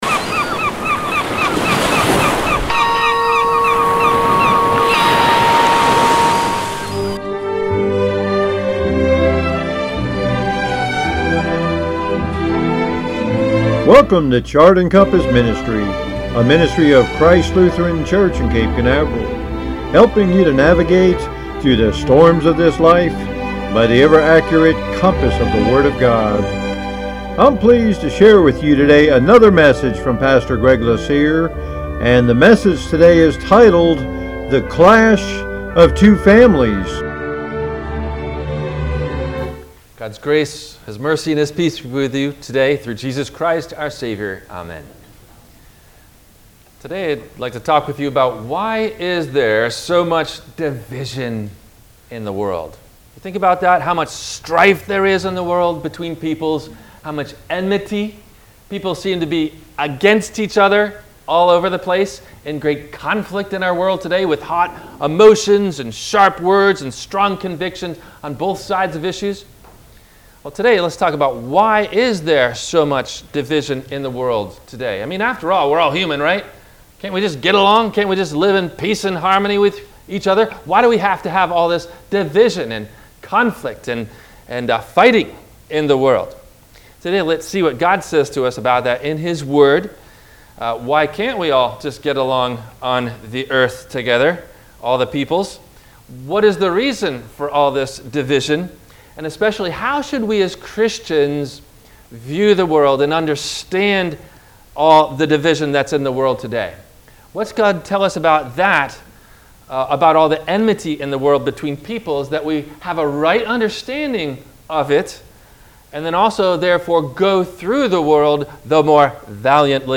The Clash of Two Families – WMIE Radio Sermon – May 30 2022 - Christ Lutheran Cape Canaveral